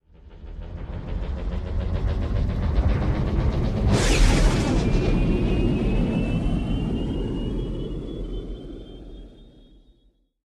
launch3.ogg